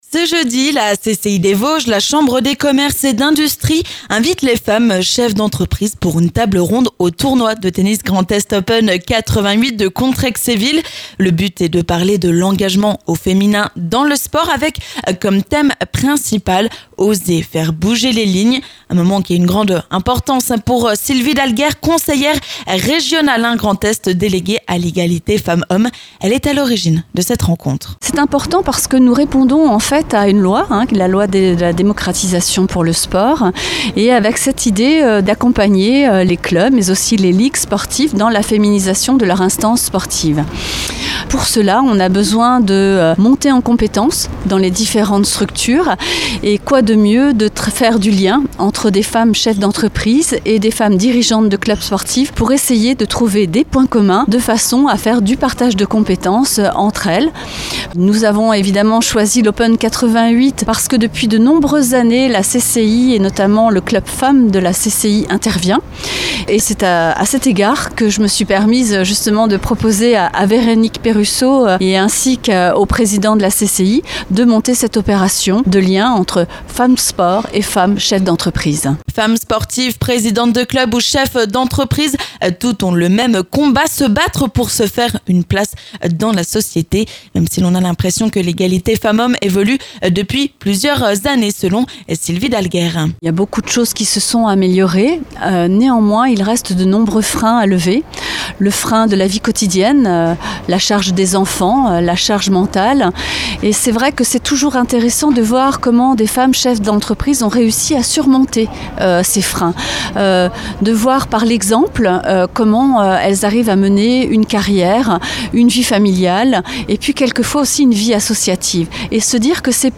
On en parle sur Vosges FM, avec Sylvie d'Alguerre, conseillère régionale Grand Est en charge de l'égalité femmes/hommes.